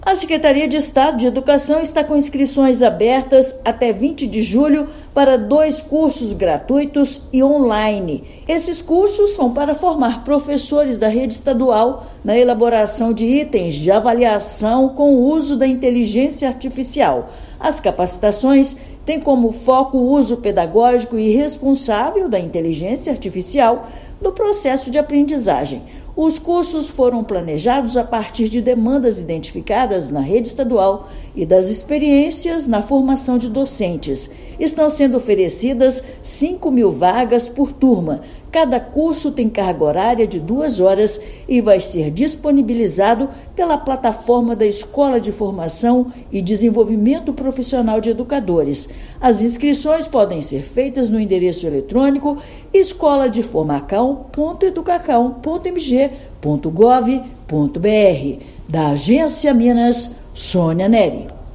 Capacitações on-line e com certificado vão ajudar educadores a elaborar e revisar itens avaliativos com apoio da inteligência artificial; inscrições vão até o próximo domingo (20/7). Ouça matéria de rádio.